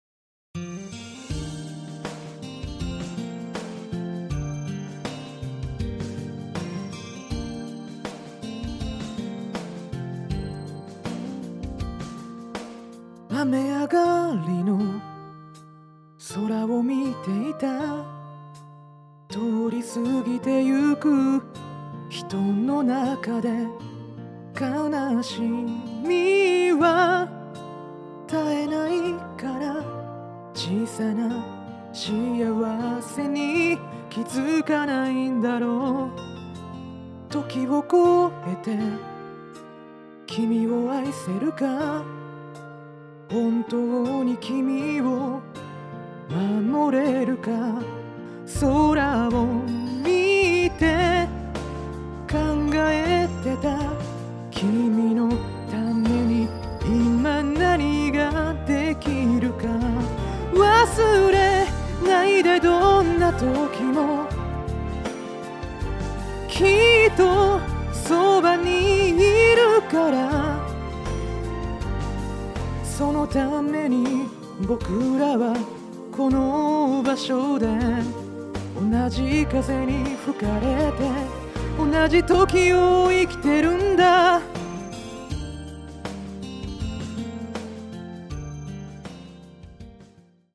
講師の歌唱音源付き記事
• 音源は一発録り、編集はコンプレッサー／イコライザー／リバーブのみ、ピッチ補正なんて出来ないししません。
音量注意！
※カラオケ音源はこちらからお借りしました。